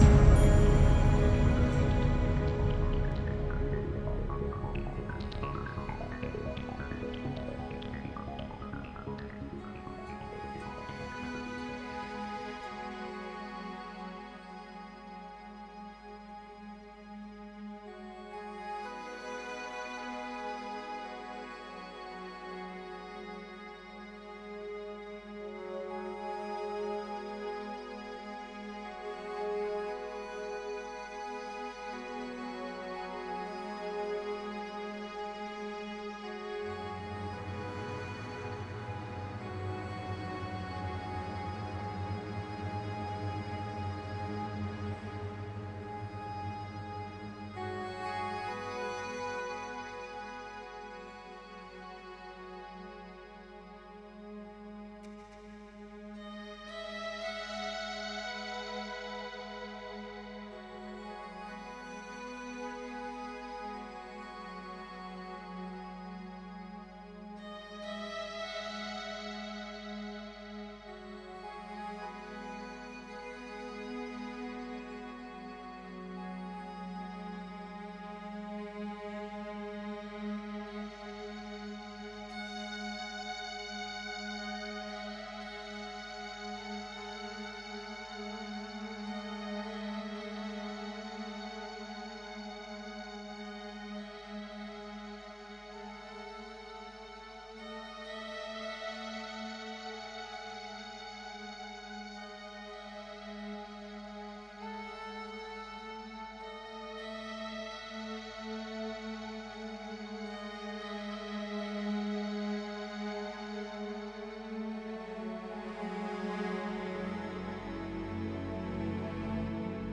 Жанр: Soundtrack.